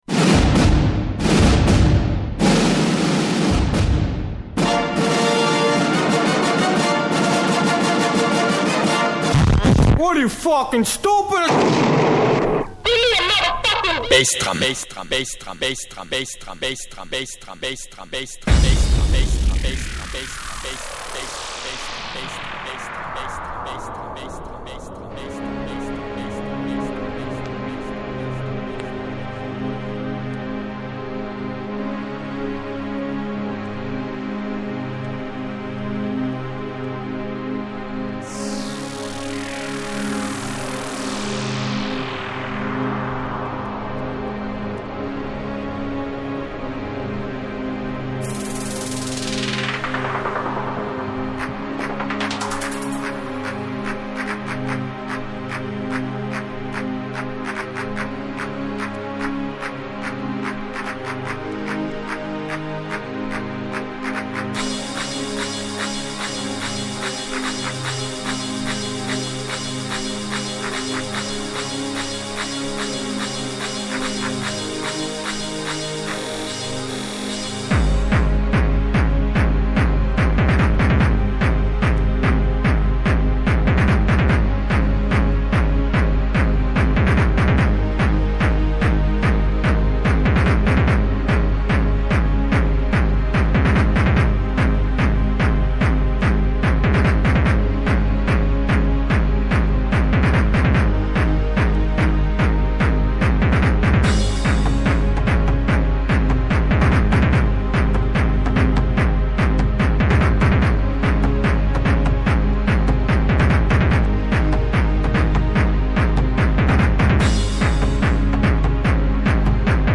Con motivo de la reciente aparición del line-up de este festival, te comentamos la fiesta y los artistas que van a actuar en ella. Además, comentamos noticias y pinchamos algunas novedades discográficas de reciente aparición en el mercado musical Hardcore.